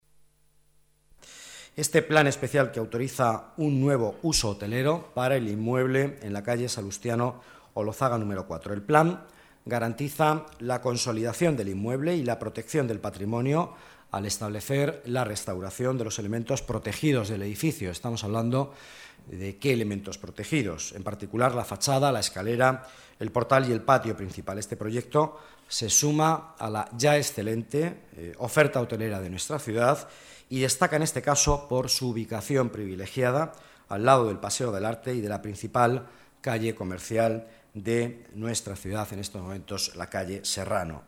Nueva ventana:Declaraciones vicealcalde, Miguel Ángel Villanueva: nuevo hotel en Salustiano Olózaga